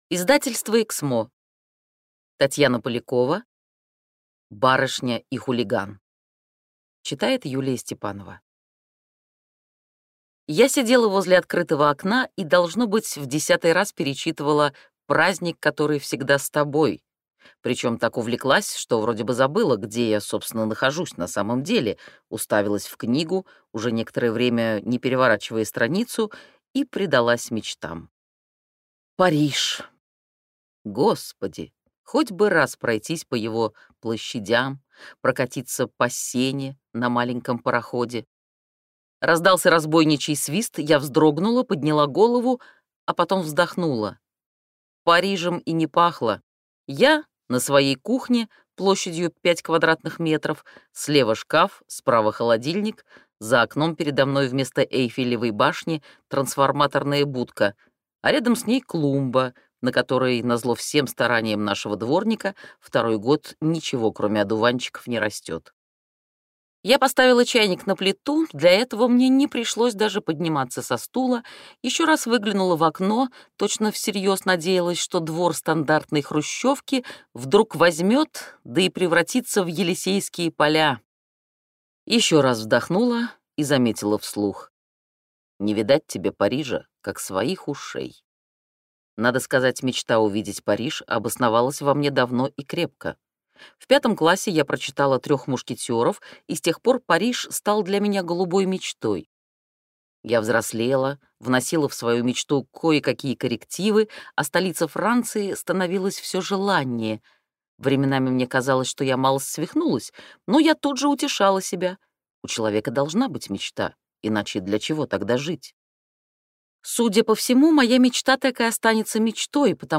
Аудиокнига Барышня и хулиган